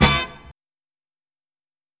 Mixed horn hit into nice-shot chime
horn_hit3.wav